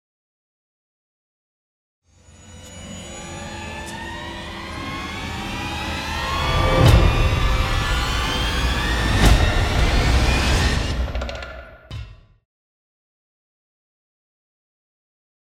Звуки из фильмов ужасов